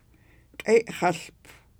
q̓ey̓x̌əłp